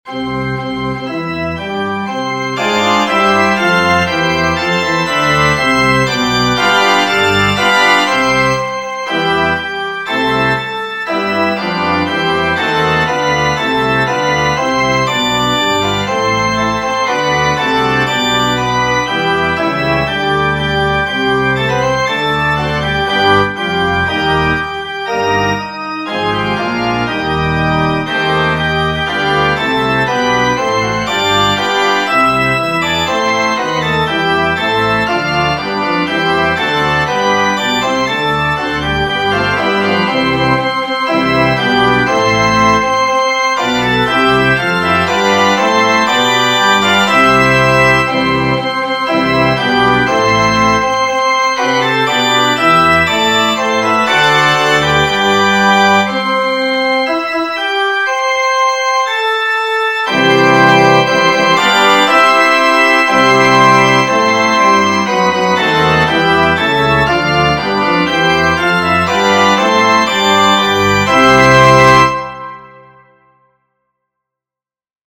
The school song - organ version
organschoolsong